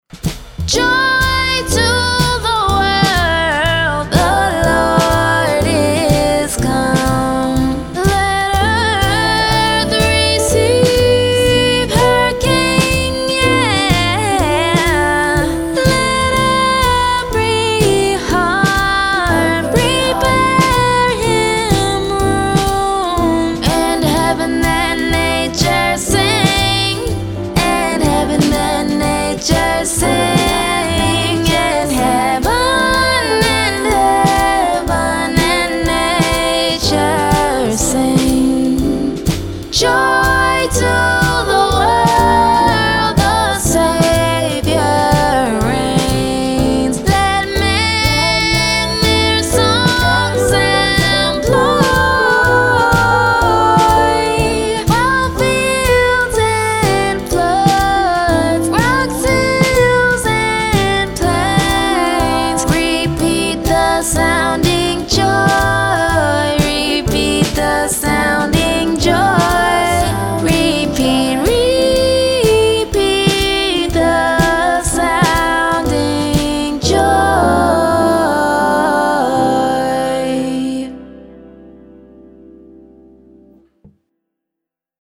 Holiday, Christmas, Jazz
C Major